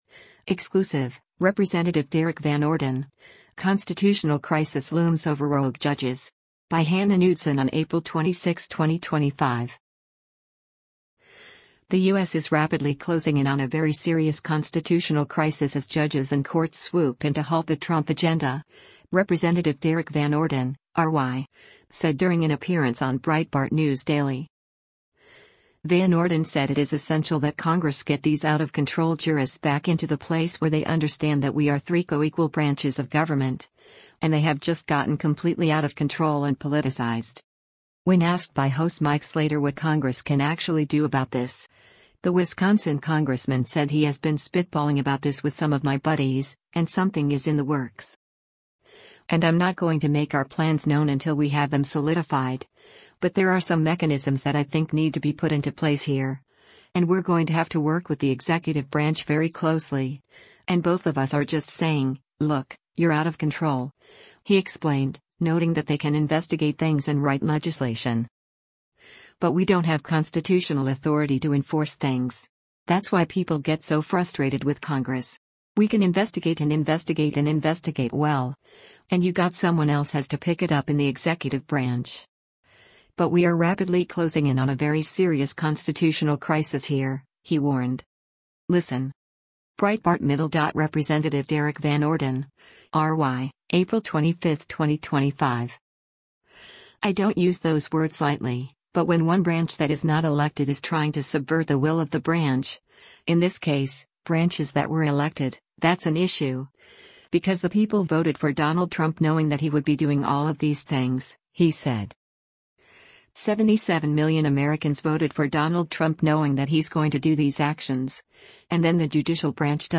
The U.S. is “rapidly closing in on a very serious constitutional crisis” as judges and courts swoop in to halt the Trump agenda, Rep. Derrick Van Orden (R-WI) said during an appearance on Breitbart News Daily.
Breitbart News Daily airs on SiriusXM Patriot 125 from 6:00 a.m. to 9:00 a.m. Eastern.